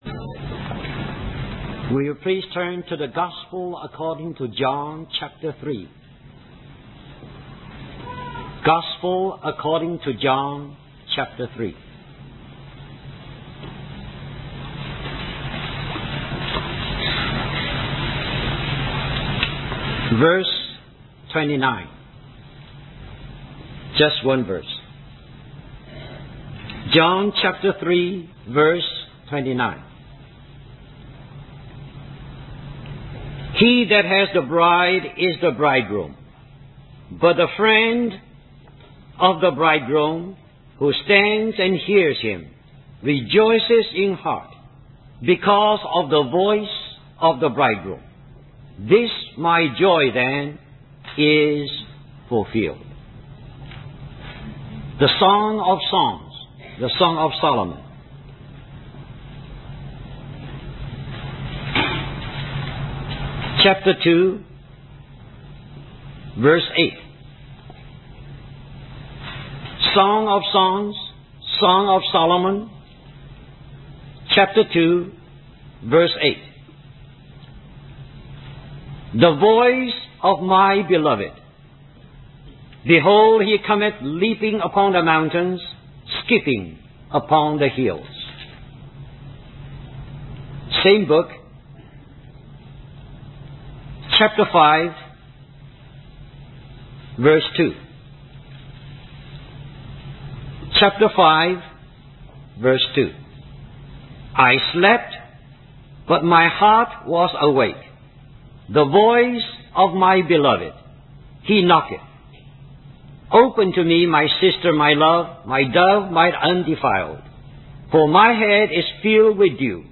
In this sermon, the preacher begins by referencing various Bible verses, including Song of Solomon 2:8, Matthew 25:6, and Song of Solomon 5:2. The sermon then focuses on the story of Simon Peter, who denied Jesus but was later forgiven and restored by the Lord. The preacher emphasizes the importance of removing any barriers in our hearts to fully experience the glory of the Lord.